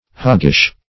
Hoggish \Hog"gish\, a.